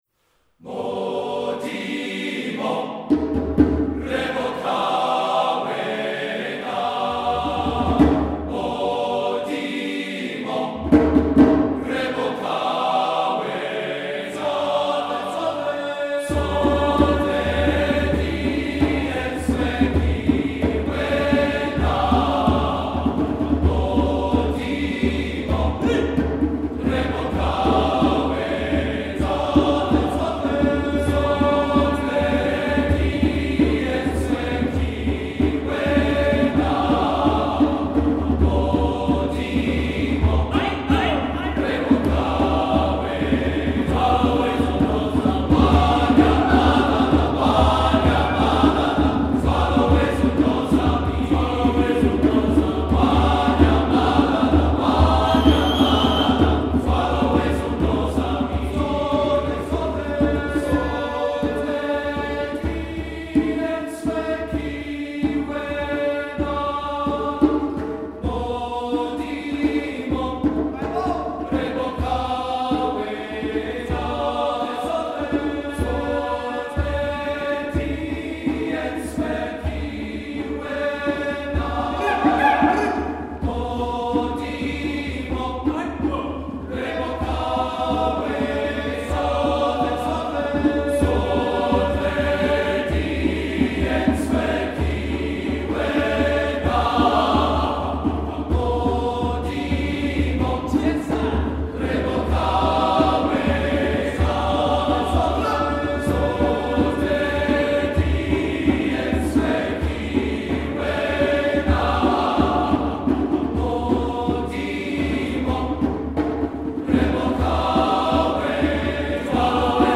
Accompaniment:      A Cappella
Music Category:      Choral
South African arrangement